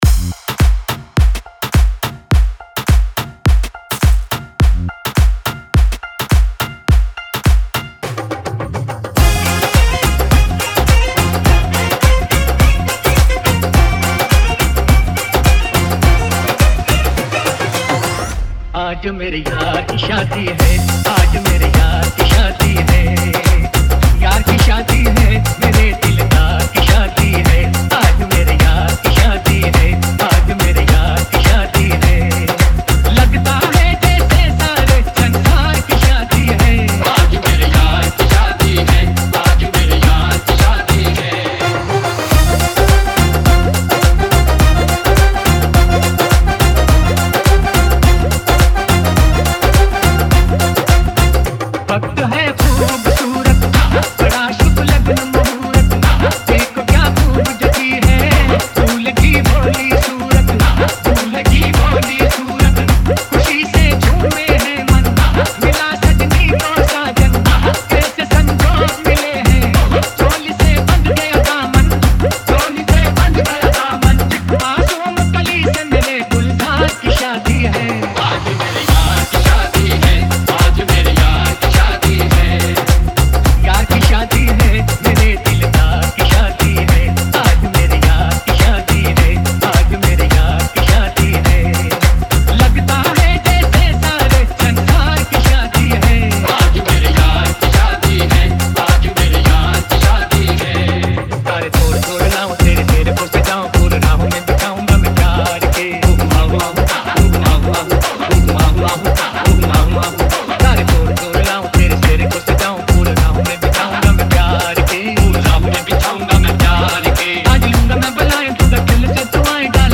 RETRO WEDDING REMIX